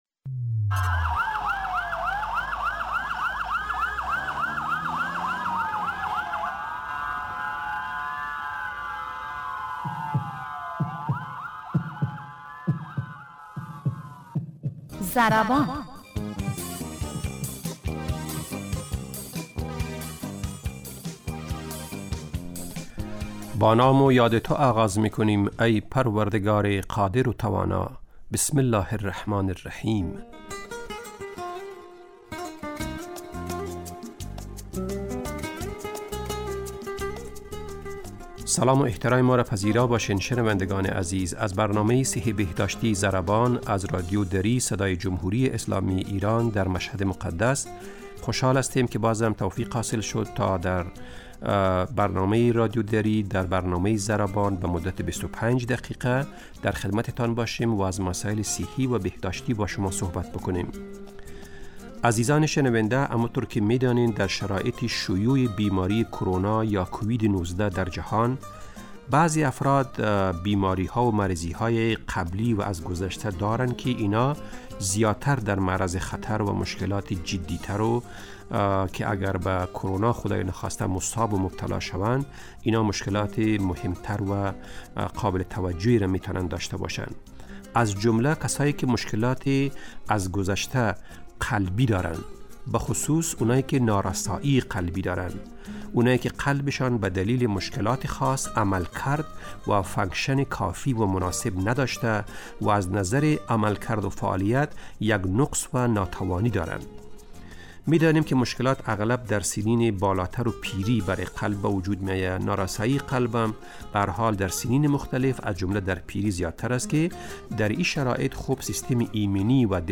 برنامه ضربان، برنامه ای صحی و بهداشتی است که با استفاده از تجربیات کارشناسان حوزه بهداشت و سلامت و استادان دانشگاه، سعی دارد مهمترین و شایع ترین مشکلات صحی...